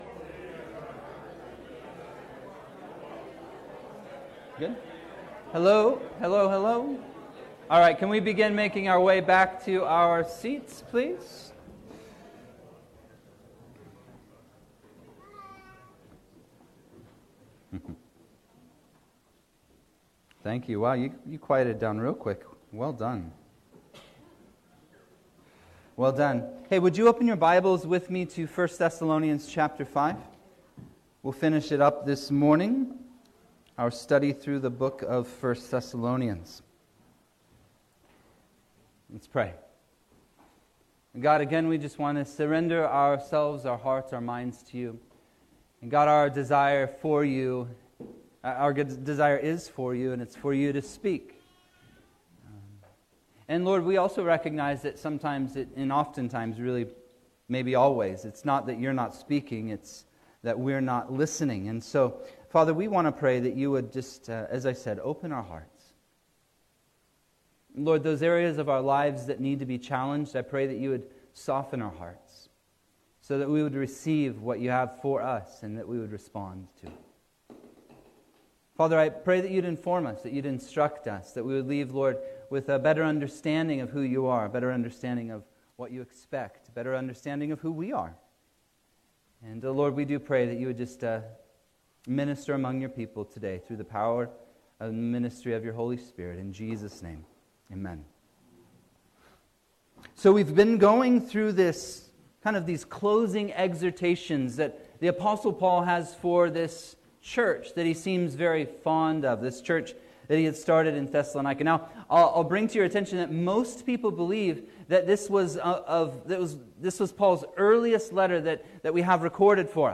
Sermons | Shoreline Calvary